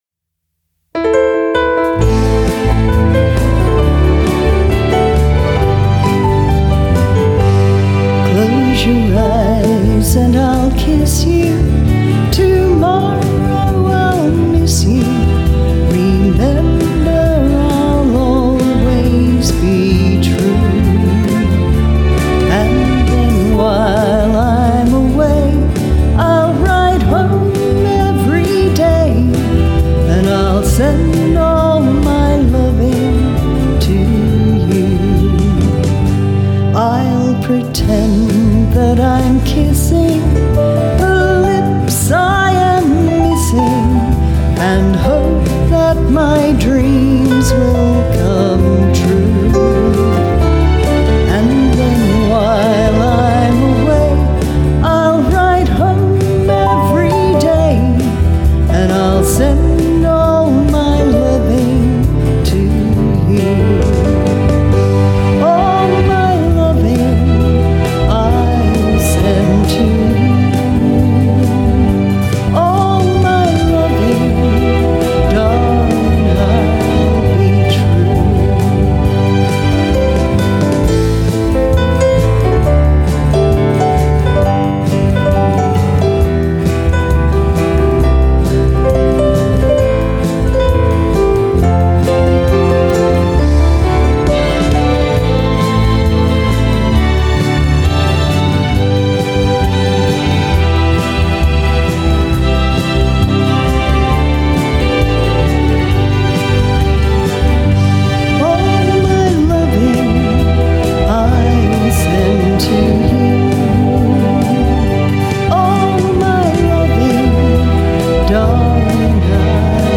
Australian country music